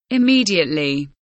immediately kelimesinin anlamı, resimli anlatımı ve sesli okunuşu